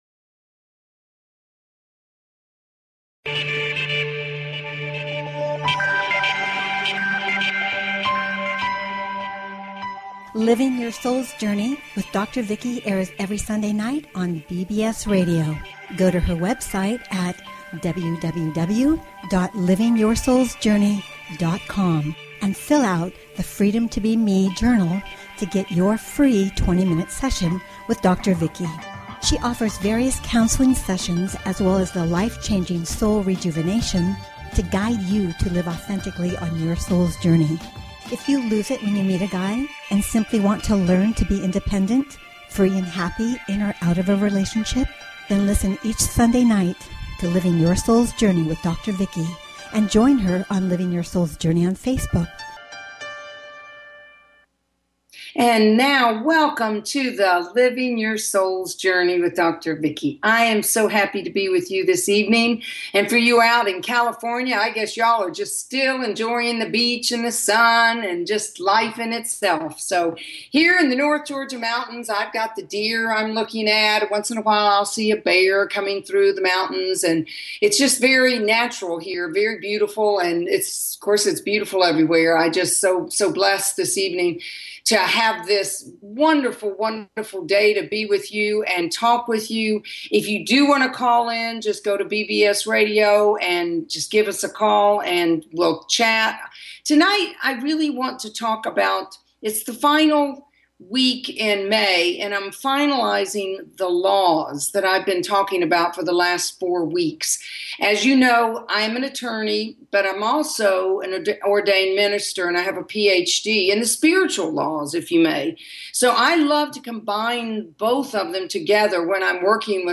Headlined Show, Living Your Souls Journey May 31, 2015